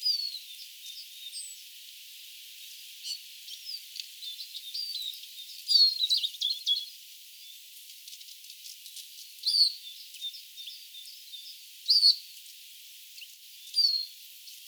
vihervarpunen vetäisee pienen tiltalttimatkinnan
vihervarpunen_vetaisee_palan_tiltaltin_laulua_vihervarpunen_on_hyva_matkimaan.mp3